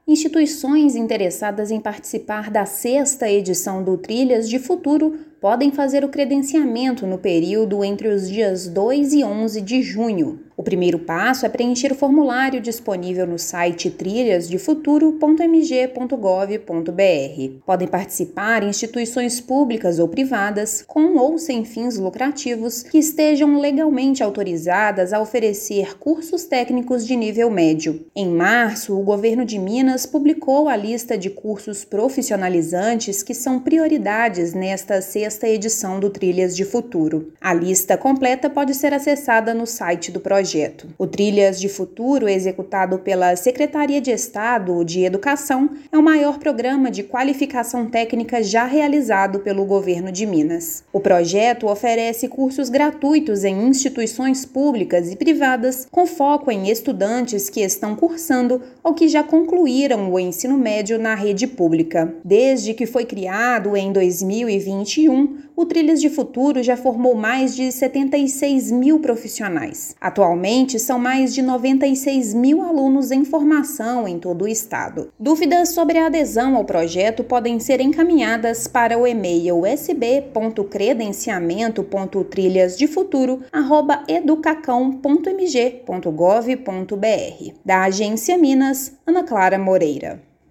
[RÁDIO] Governo de Minas publica edital de credenciamento de instituições para a 6ª edição do Trilhas de Futuro
Instituições de ensino profissionalizantes interessadas em oferecer cursos técnicos podem se inscrever de 2 a 11/6. Ouça matéria de rádio.